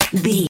Aeolian/Minor
synthesiser
drum machine
hip hop
Funk
neo soul
acid jazz
confident
energetic
bouncy
funky